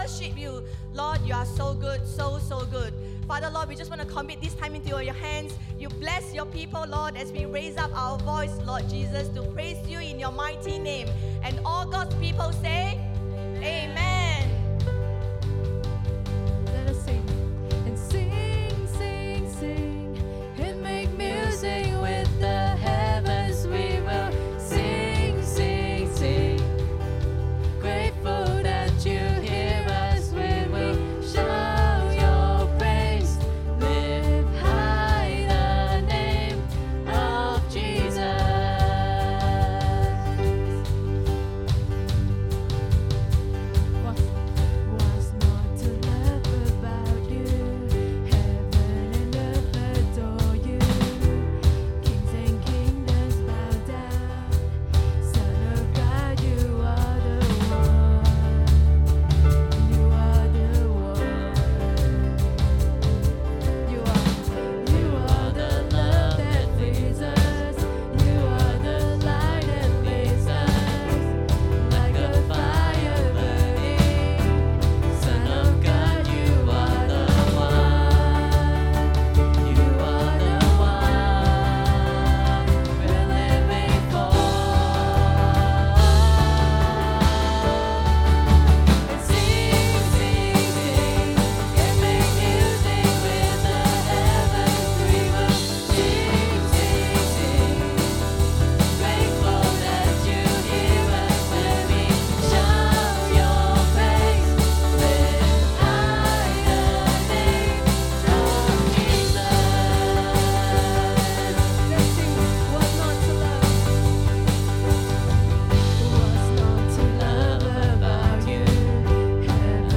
English Worship Service - 21st May 2023
Sermon Notes